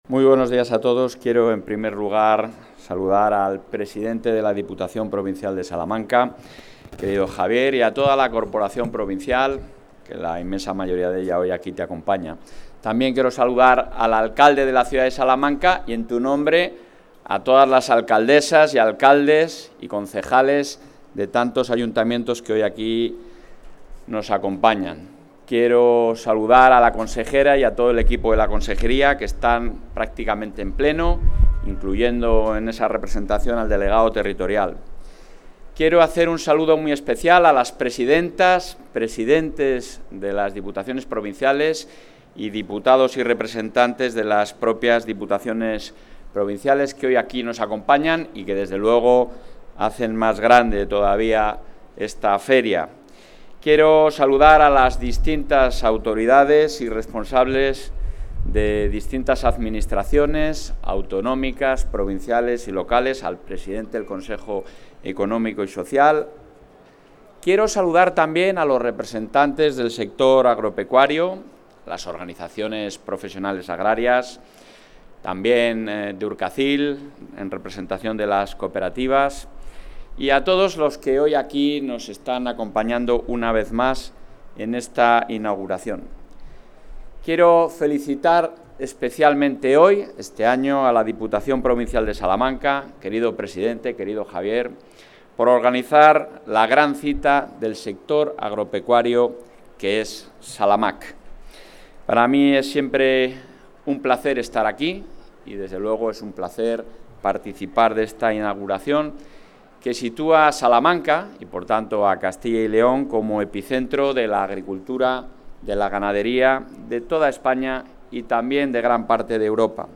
Intervención del presidente de la Junta.
Durante el acto oficial de inauguración de la Feria del Sector Agropecuario y 36 Exposición Internacional de Ganado Puro ´SALAMAQ 25’, el presidente de la Junta de Castilla y León, Alfonso Fernández Mañueco, ha reiterado el compromiso del Ejecutivo autonómico con los agricultores y ganaderos, aportando soluciones a través del diálogo con el sector.